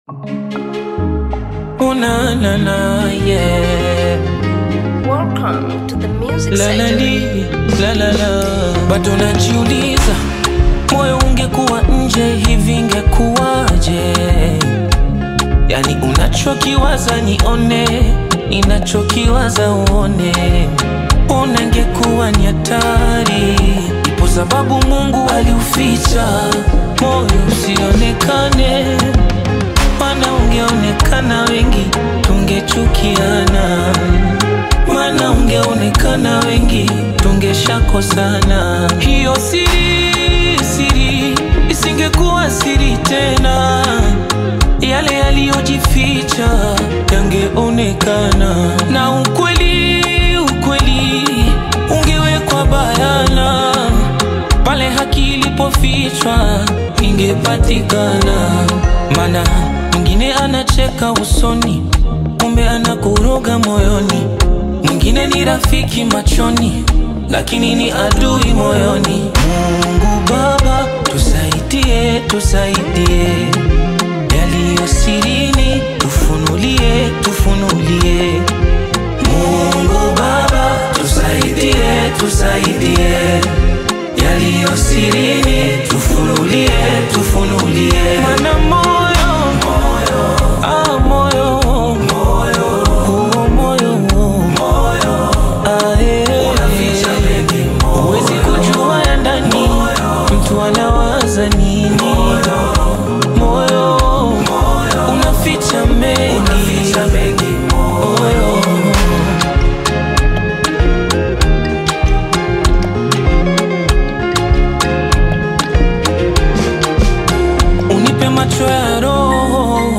gospel
is a heartfelt gospel song that speaks directly to the soul.